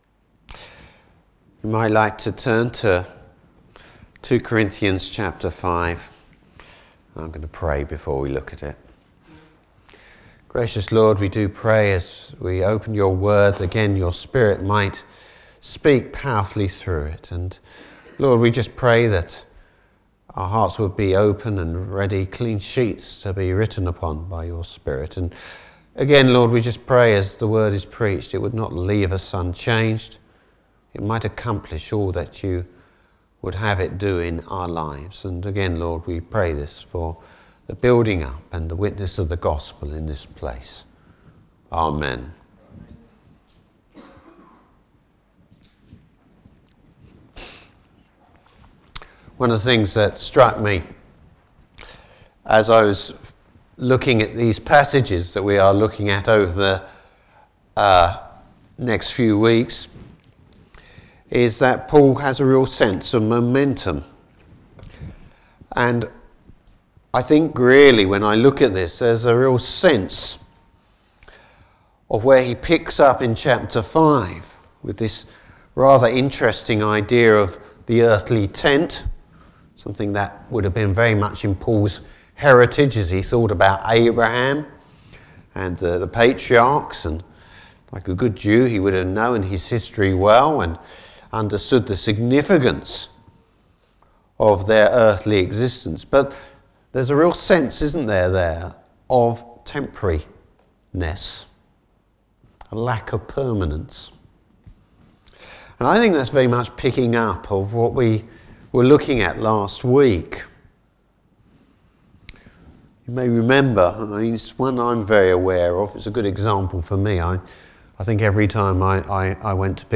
Passage: 2 Corinthians 5. Service Type: Morning Service Bible Text: 2 Corinthians 5.